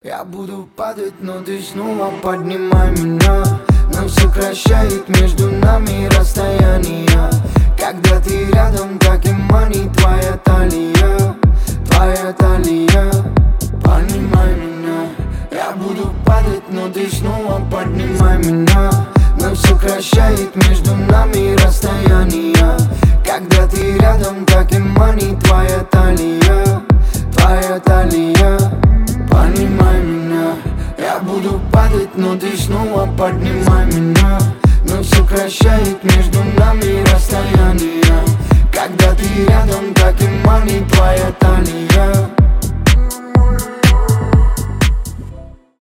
рэп , поп